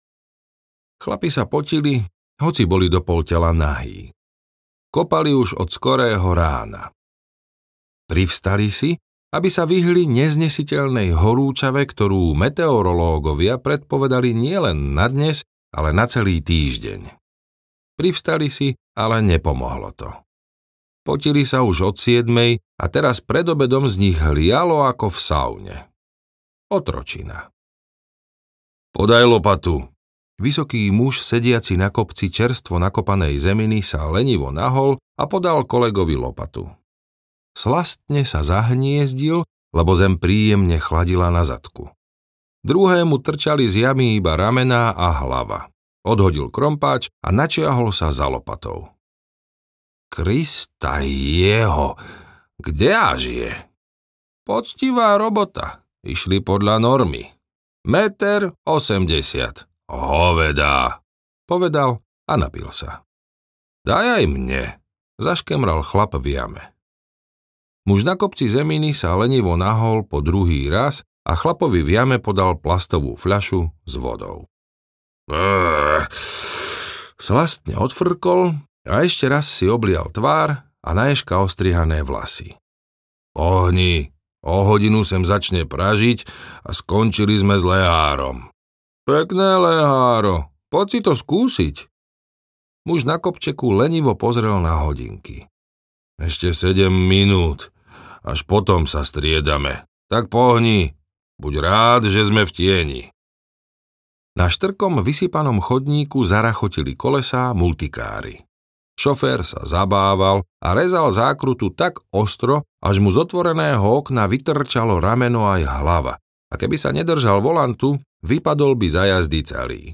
Červený kapitán audiokniha
Ukázka z knihy
cerveny-kapitan-audiokniha